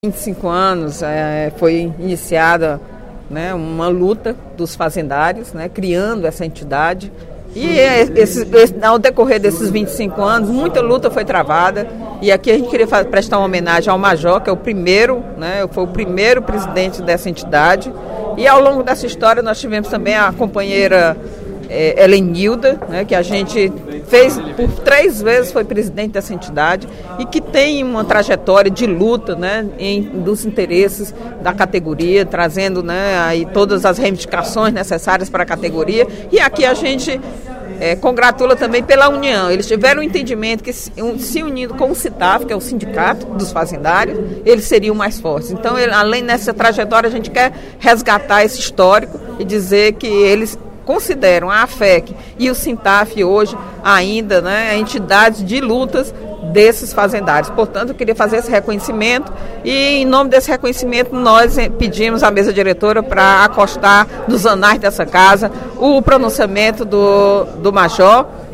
A deputada Eliane Novais (PSB) ressaltou, na sessão plenária da Assembleia Legislativa desta quinta-feira (24/05), os 25 anos de existência da Associação dos Aposentados Fazendários Estaduais do Ceará (AAFEC).